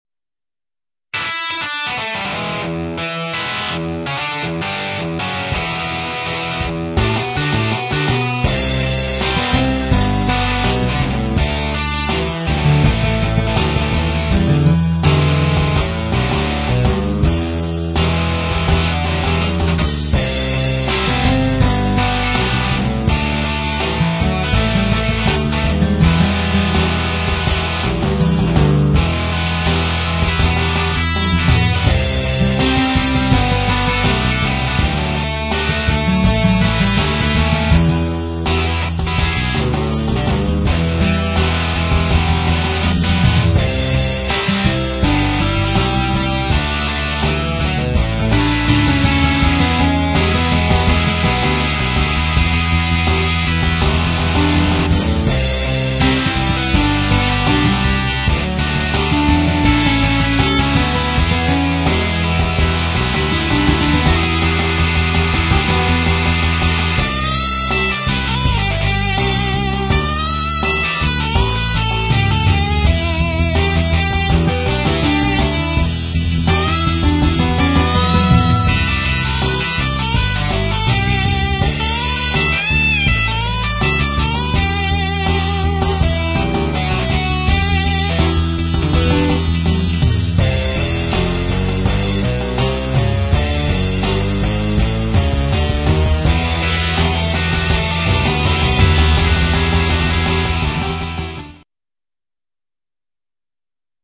SOLO PART
heyjoesolo.mp3